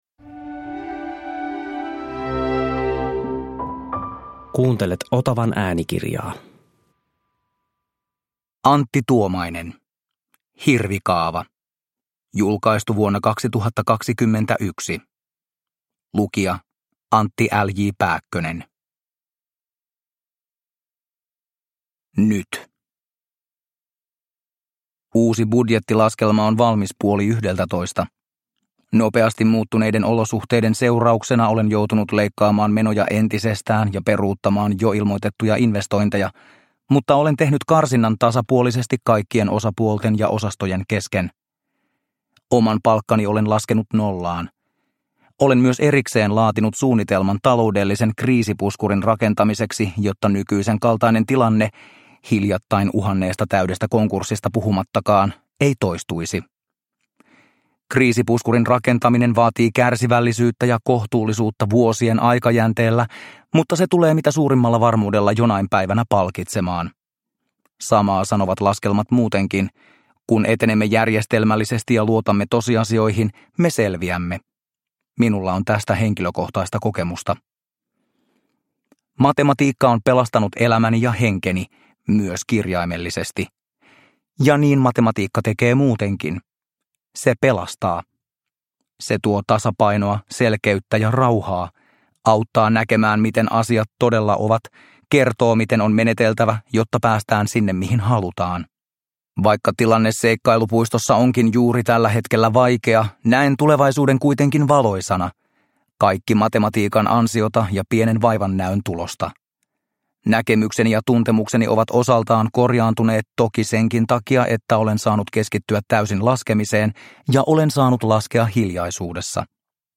Hirvikaava – Ljudbok